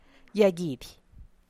Teotitlán del Valle Zapotec Talking Dictionary